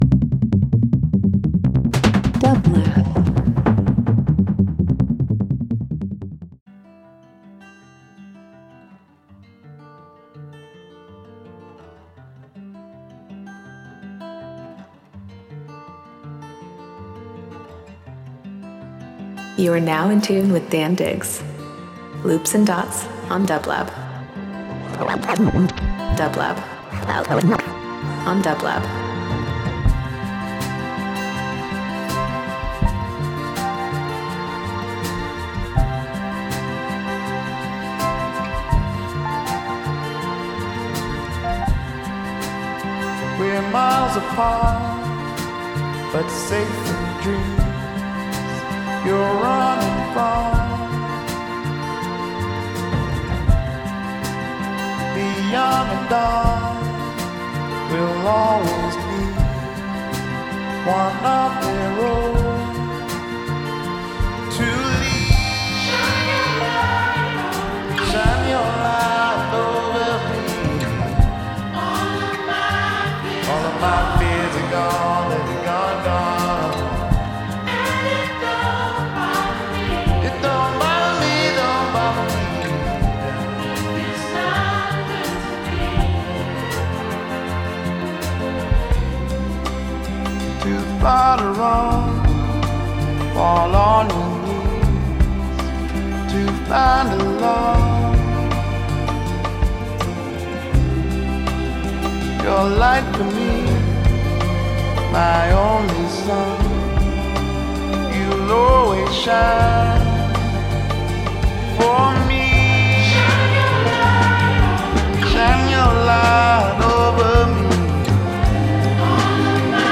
Beats Electronic Funk/Soul Leftfield